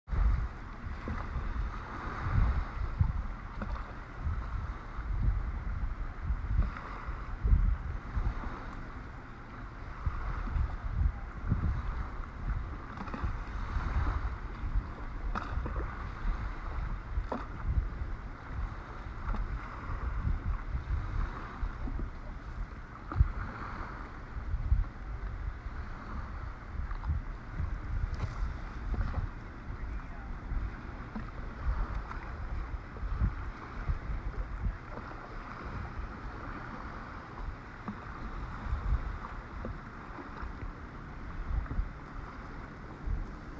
Download Harbor sound effect for free.
Harbor